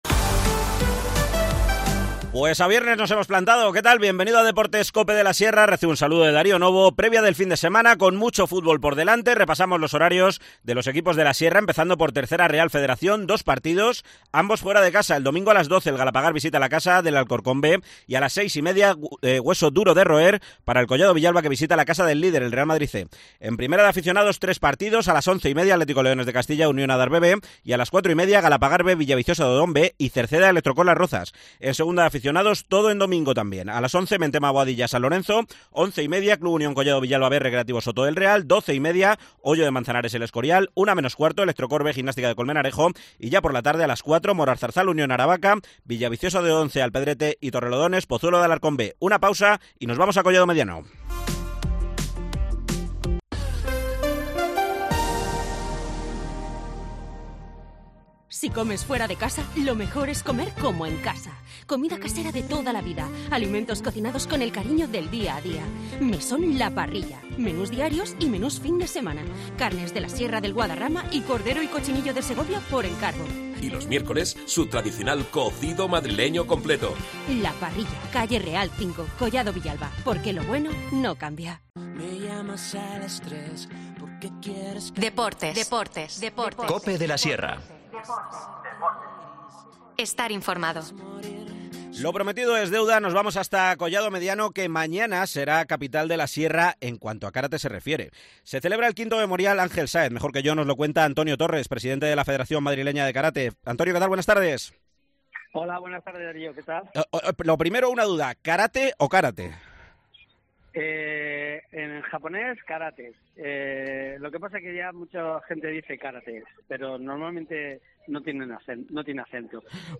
Deportes local